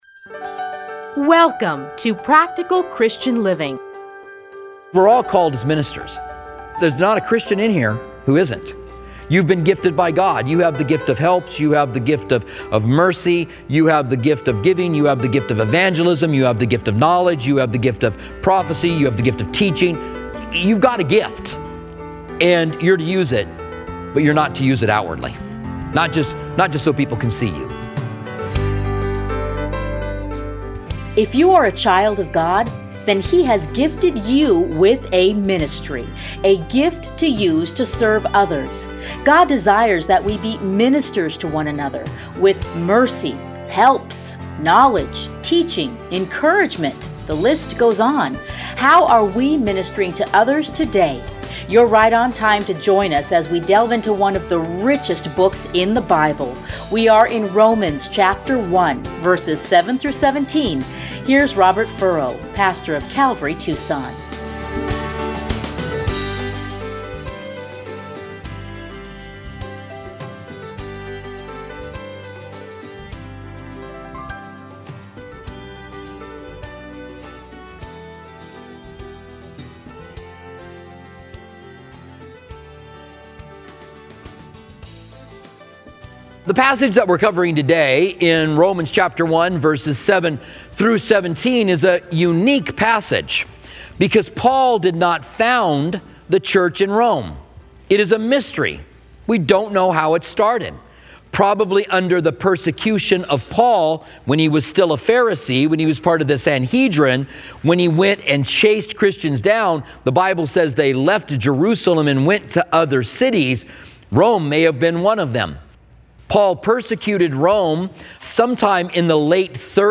Listen here to his commentary on Romans.